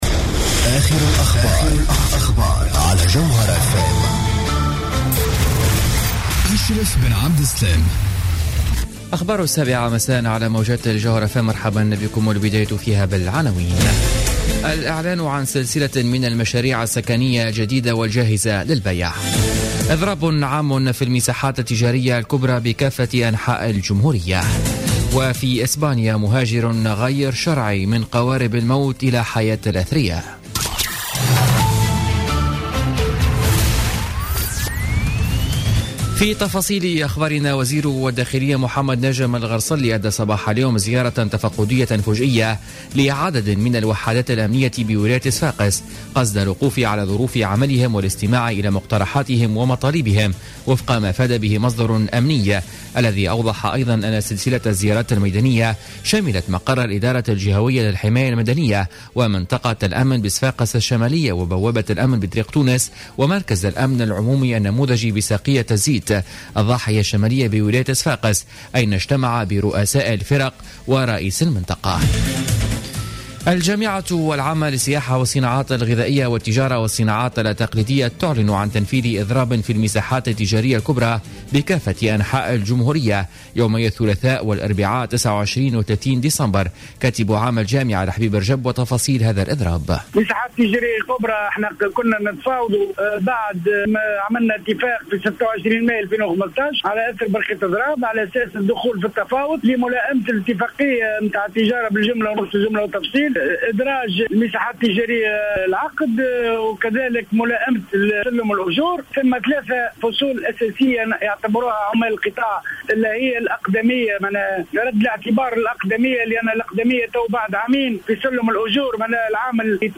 نشرة أخبار السابعة مساء ليوم الخميس 24 ديسمبر 2015